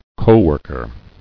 [co·work·er]